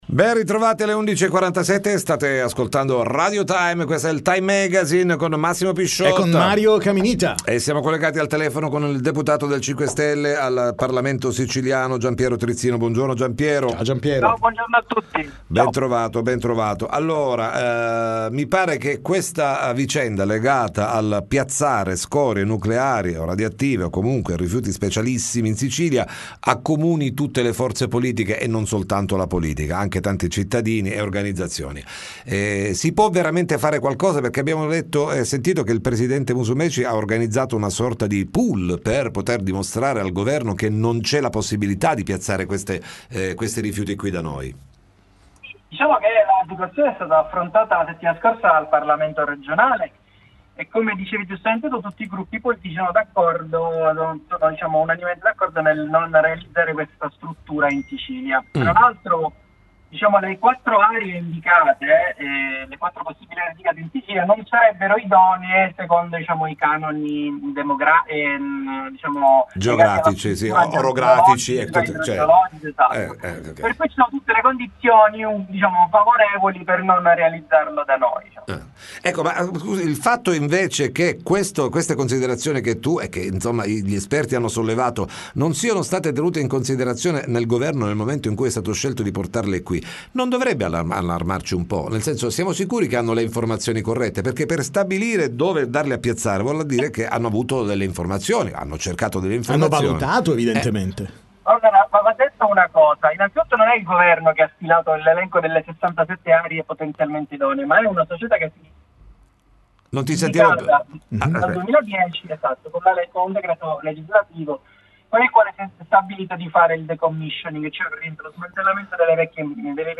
TM intervista Giampiero Trizzino